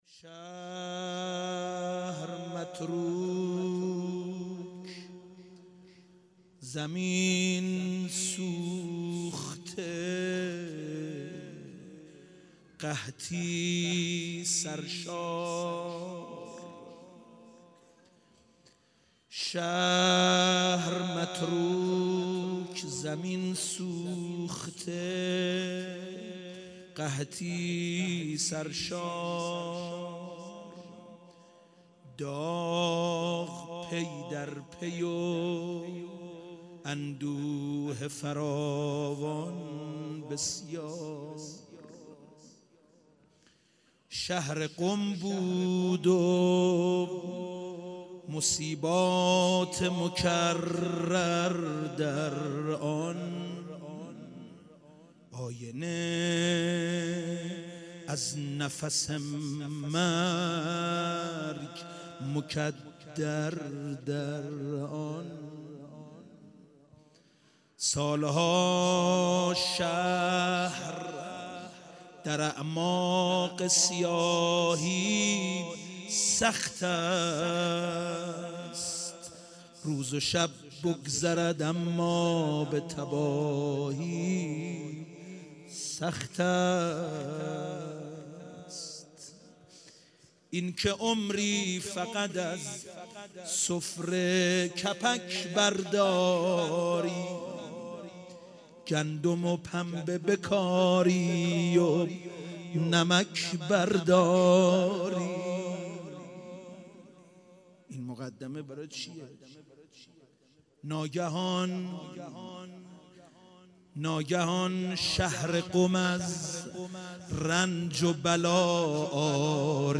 شهادت حضرت معصومه سلام الله علیها _روضه